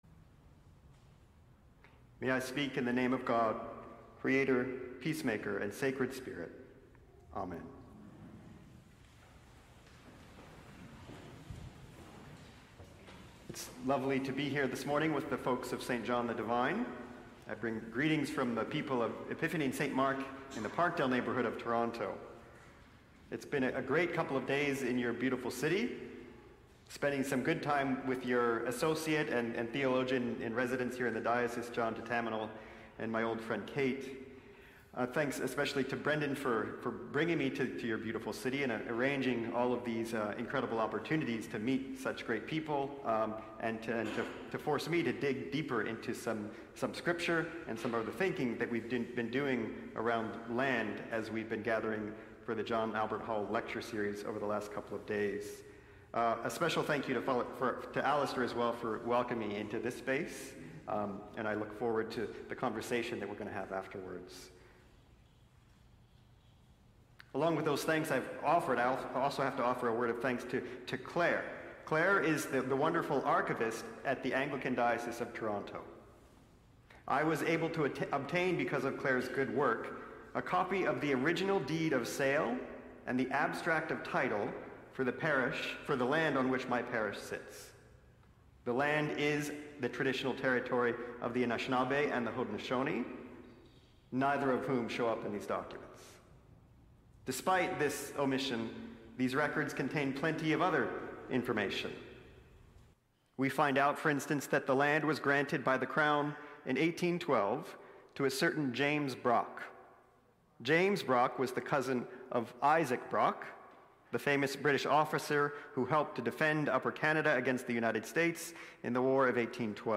Sermons | St. John the Divine Anglican Church